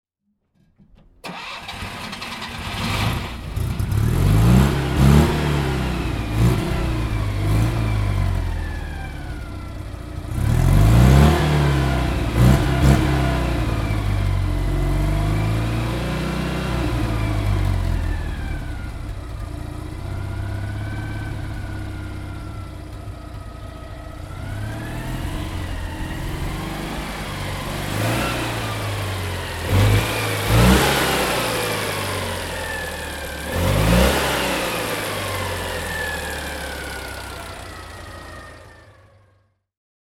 Citroën Méhari 4x4 (1980) - Starten und Leerlauf